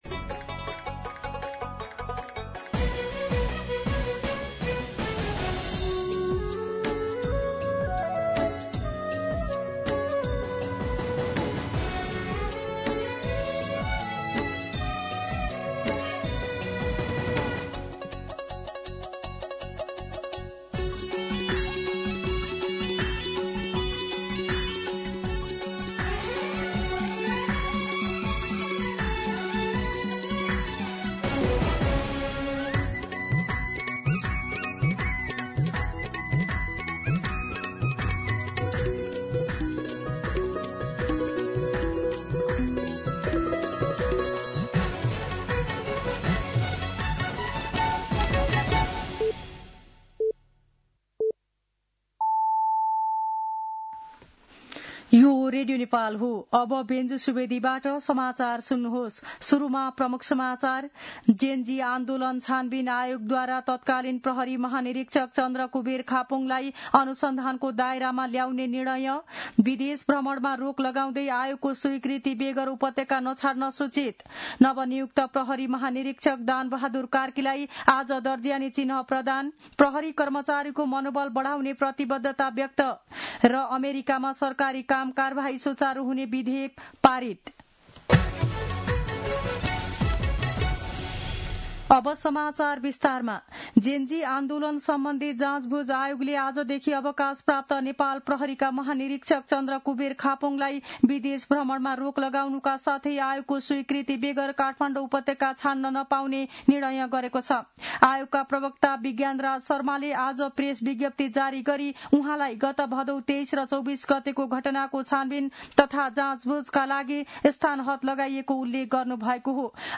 दिउँसो ३ बजेको नेपाली समाचार : २७ कार्तिक , २०८२